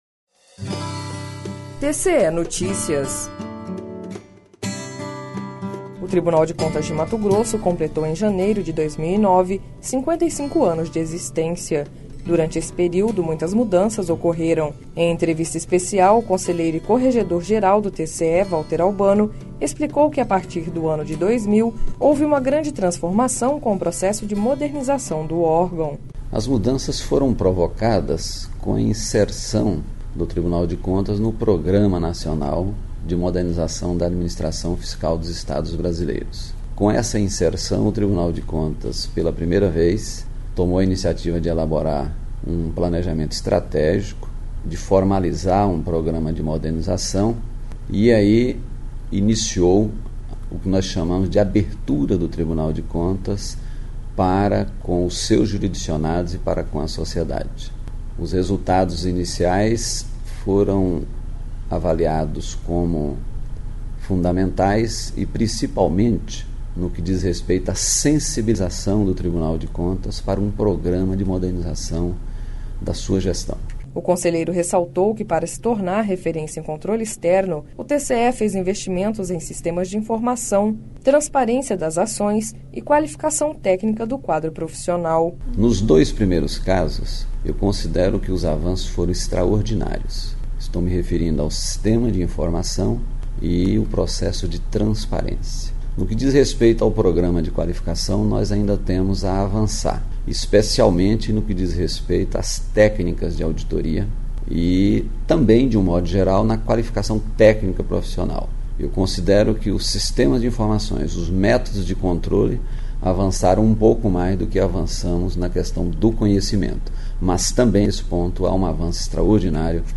Em entrevista especial, o conselheiro e corregedor geral do TCE, Valter Albano, explicou que a partir do ano 2000 houve uma grande transformação com o processo de modernização do órgão.